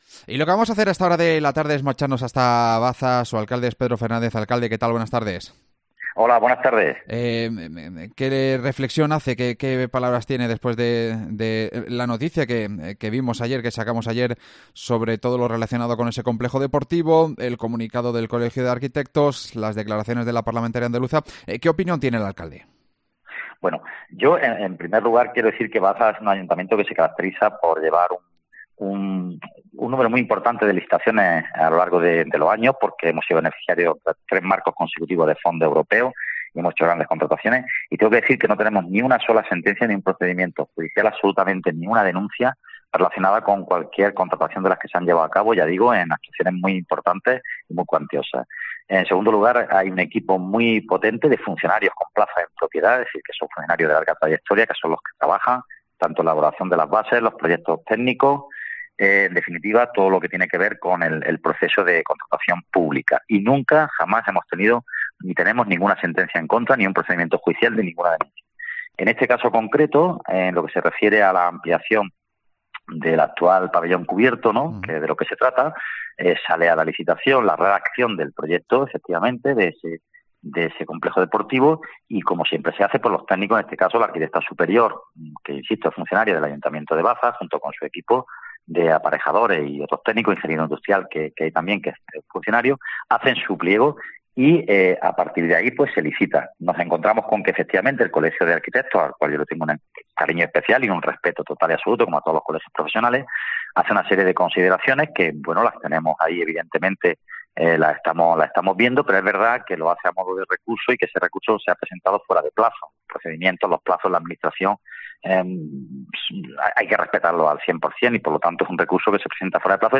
AUDIO: El alcalde de Baza habla en COPE sobre la polémica del proyecto de ampliación del centro deportivo